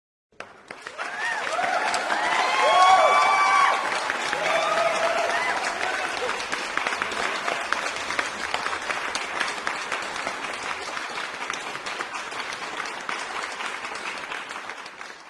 Aplausos.mp3
KEzvrasp0Al_Aplausos.mp3